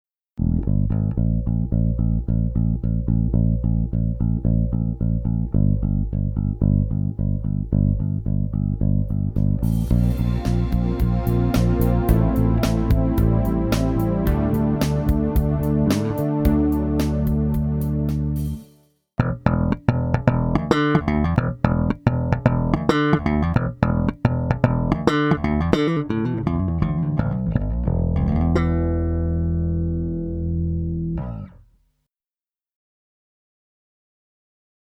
E-Bass
Zupfinstrumente
• Der Elektrobass gehört zur Gitarrenfamilie, klingt aber, wie dem Namen zu entnehmen ist, tief wie ein Kontrabass. Der Klang entsteht durch die schwingenden Saiten und den Korpus und gelangt via Tonabnehmer auf den Verstärker.
09-E-Bass.mp3